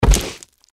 watcher_foot_run_l03.mp3